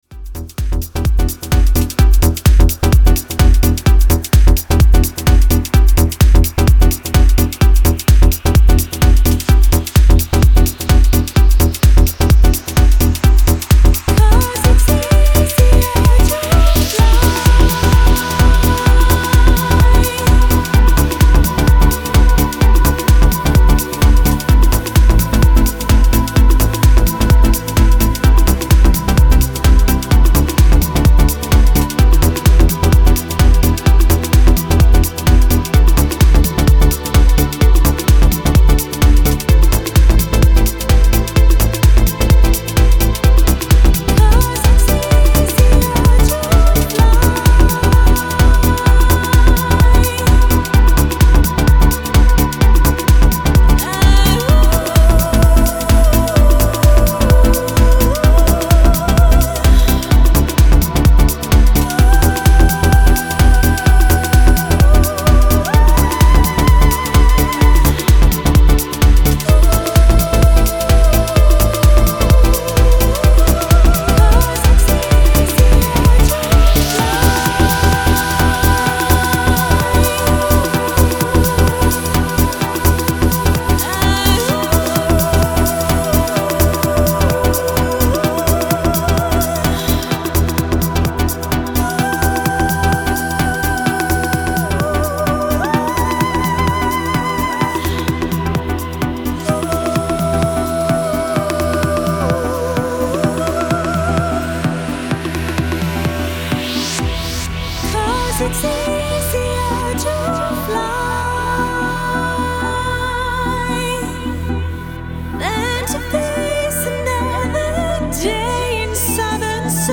The club mix
haunting vocals from female singer